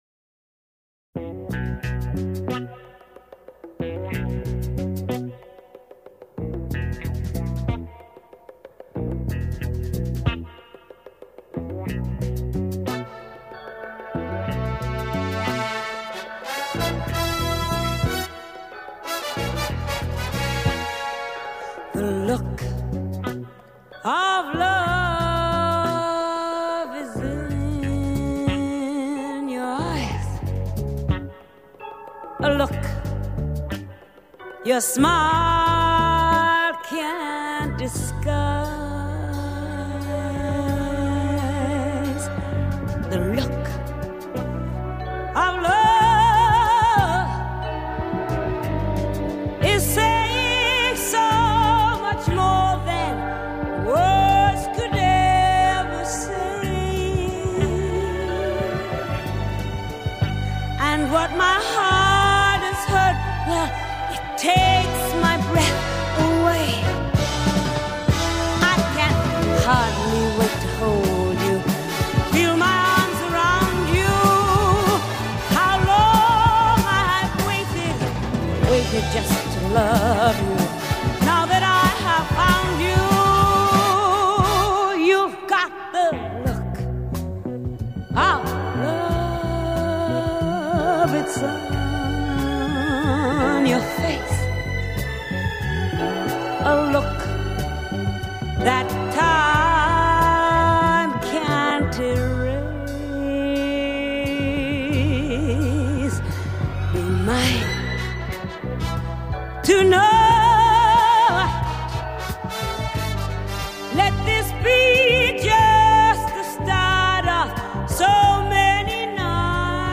Genre: Jazz